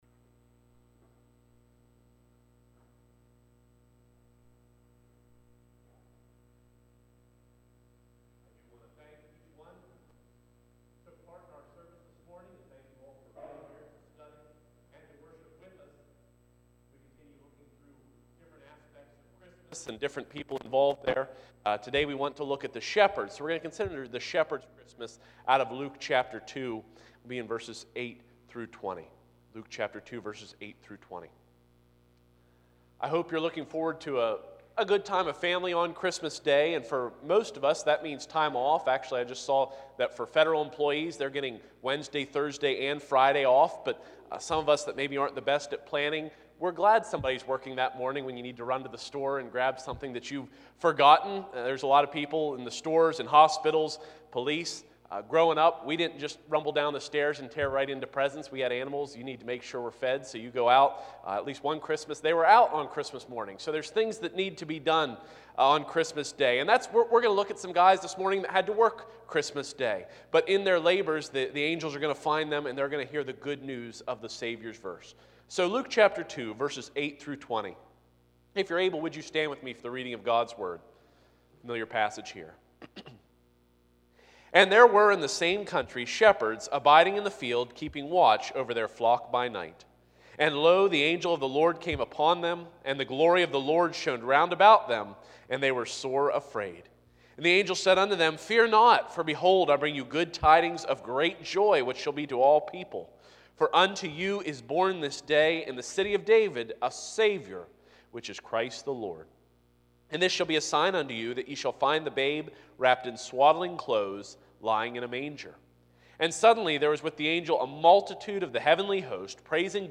Passage: Luke 2:8-20 Service Type: Sunday 9:30AM I. The Background II.